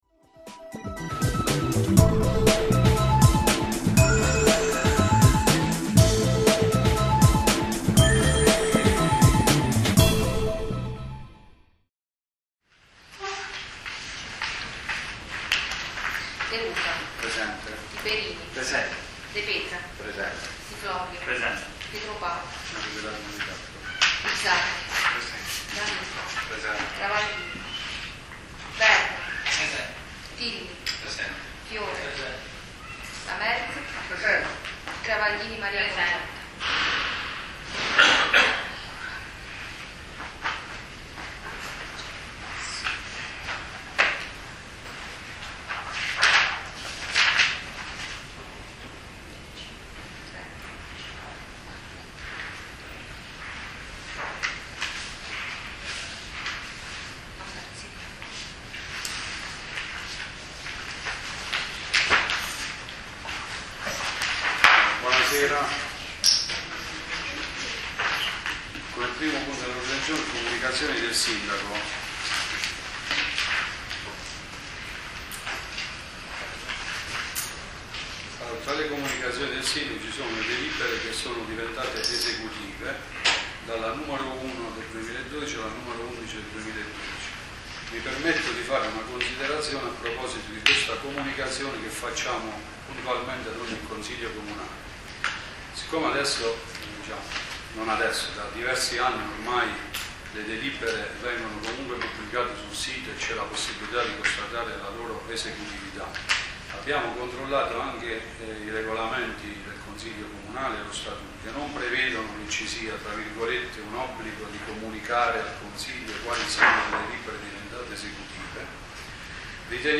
Ascolta il Consiglio Comunale del 30 Luglio 2012
Assente il Consigliere di maggioranza Filippo Travaglini Il Presiente del Consiglio Domenico Pietropaolo entra dopo 40 minuti dall'inizio della seduta (al sesto punto all'ordine del giorno).